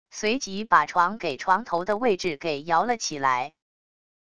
随即把床给床头的位置给摇了起来wav音频生成系统WAV Audio Player